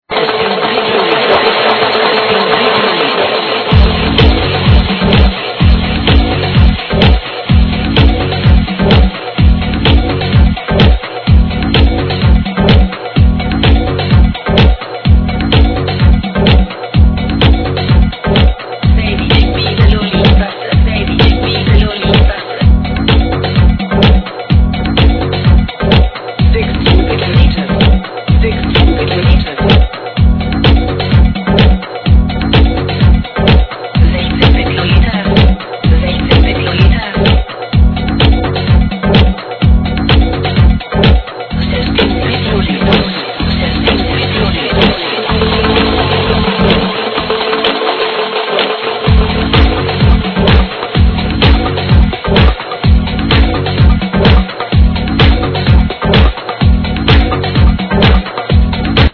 Exclamation 2005 House Tune == Played By A Lot of House DJs